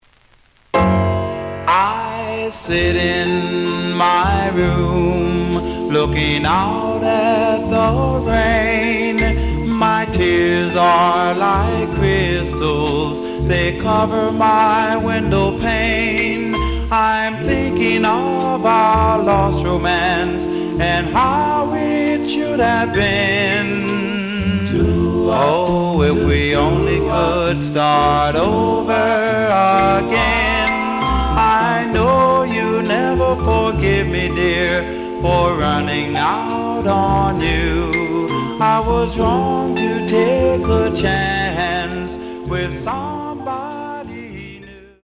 The audio sample is recorded from the actual item.
●Format: 7 inch
●Genre: Rhythm And Blues / Rock 'n' Roll
Edge warp.